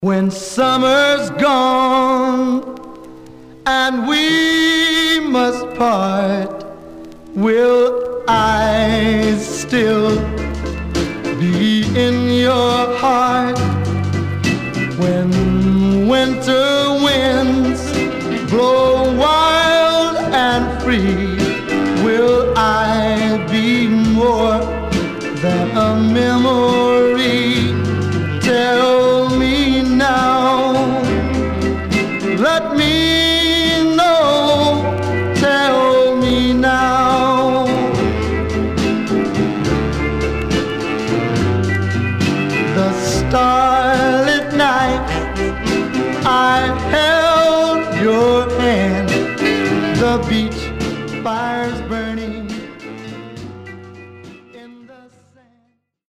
Some surface noise/wear
Mono
Teen